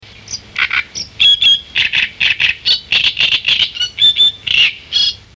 Rousserolle turdoïde
On la voit ici animer la roselière par son chant rauque et sonore.
rousserolle_t.mp3